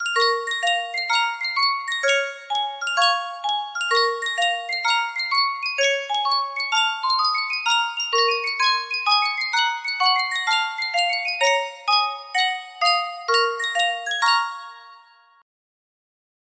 Full range 60